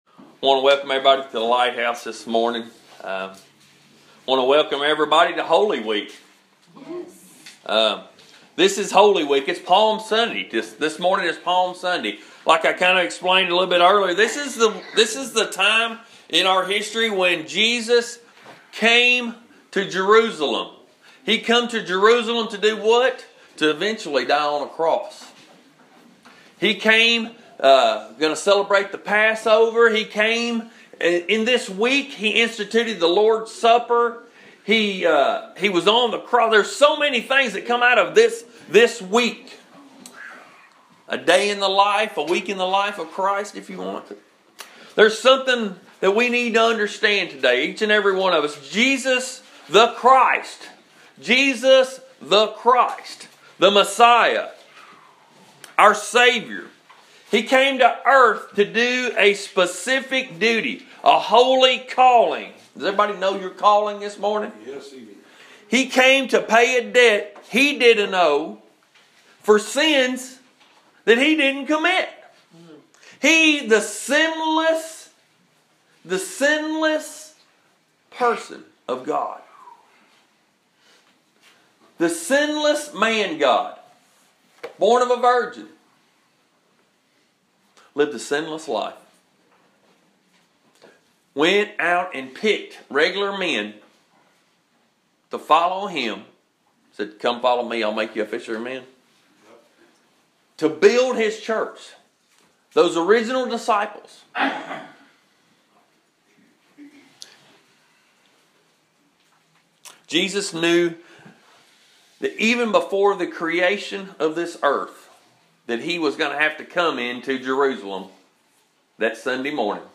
This Palm Sunday sermon was preached at The Lighthouse and really drives home the fact that popularity doesn't mean your right.